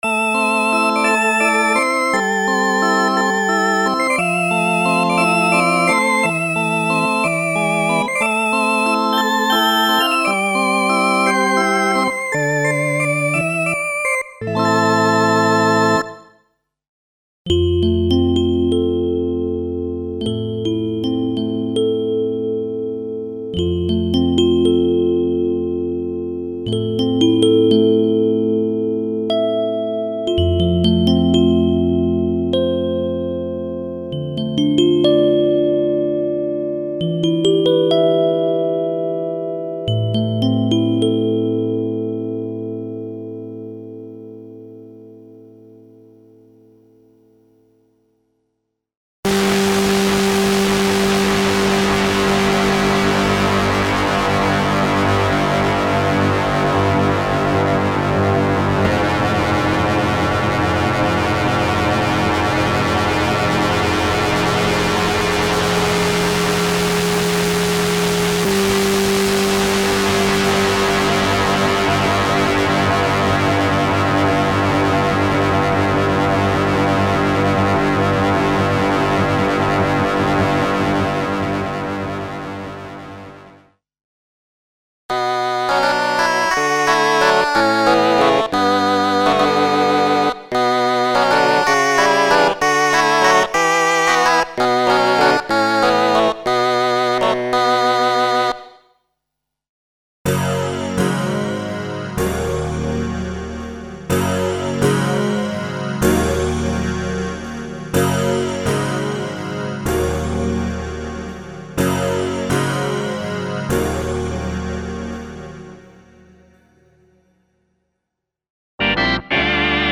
Original collection of piano and organ sound programs in experimental and 70s retro feeling, created to take advantage of the special controller settings for internal DSP modulations (e.g. filter, pitch, distortion, shaper, etc).
Info: All original K:Works sound programs use internal Kurzweil K2661 ROM samples exclusively, there are no external samples used.